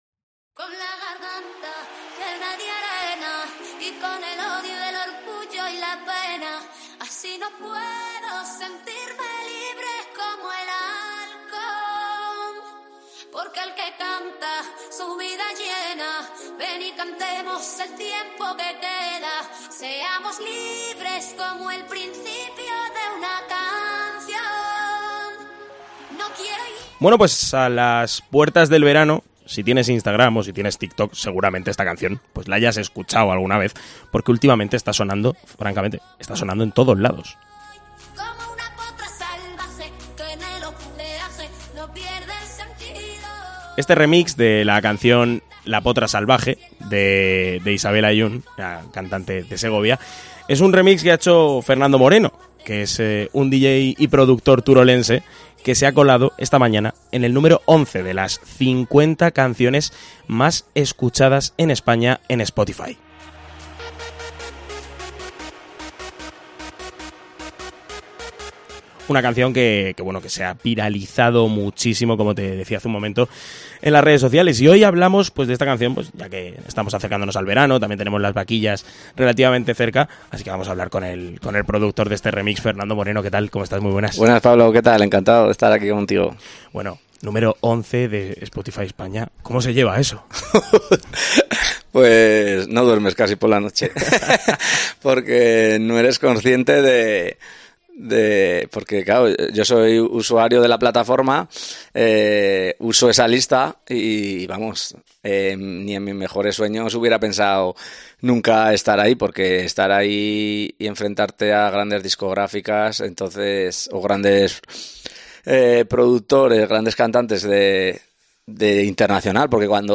se ha pasado por los micrófonos de COPE para hablar sobre este boom de 'Potra Salvaje' .